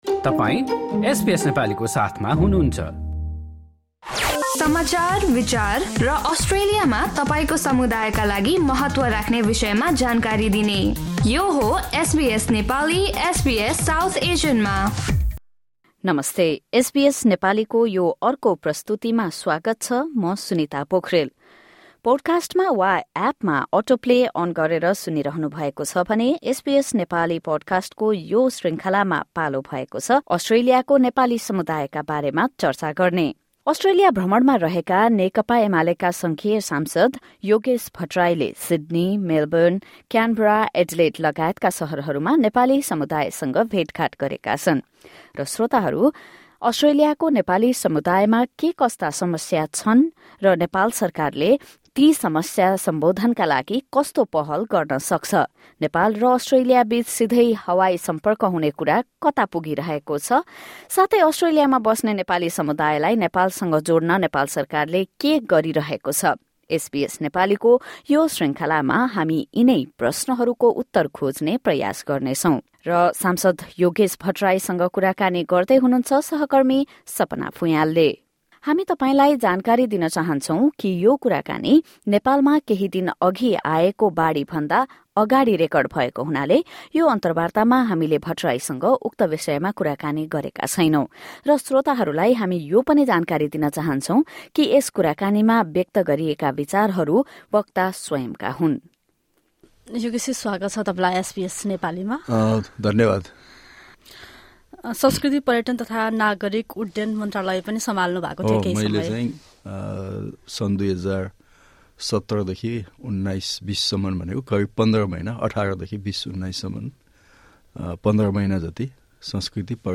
नेकपा एमालेका सङ्घीय सांसद योगेश भट्टराईले आफ्नो अस्ट्रेलिया भ्रमणमा रहेका सिड्नी, मेलबर्न, क्यानबरा, एडिलेड लगायतका शहरहरूमा नेपाली समुदायसँग भेटघाट गरेका छन्। अस्ट्रेलियाको नेपाली समुदायमा के कस्ता समस्या छन् र समाधानका लागि नेपाल सरकारले के गरिरहेको छ लगायत नेपाल र अस्ट्रेलियाबिच सिधै हवाई सम्पर्क हुने कुरा कता पुगिरहेको छ भन्ने बारेमा सांसद भट्टराईसँग एसबीएस नेपालीले गरेको कुराकानी सुन्नुहोस्।
Yogesh Bhattarai, a federal parliamentarian of Nepal at SBS Melbourne studios on Friday, 28 September 2024.